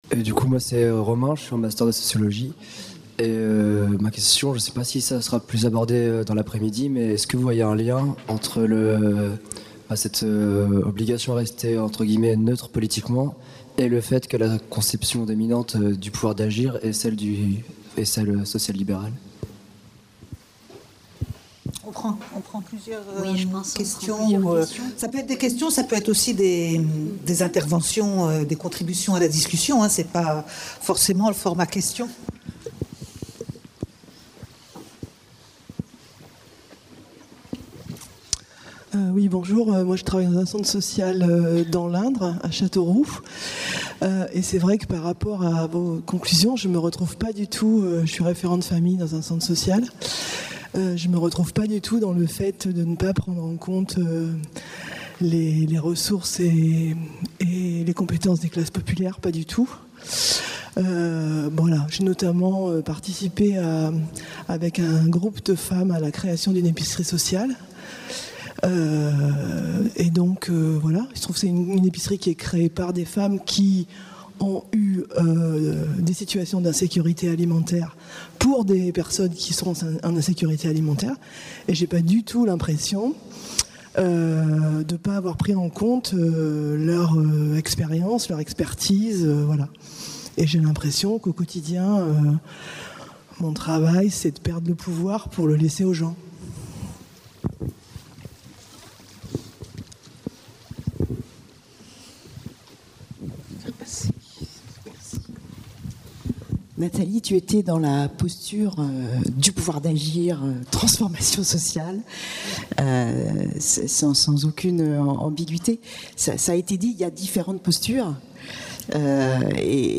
05- (Table ronde 1) : Débat avec le public (1) | Canal U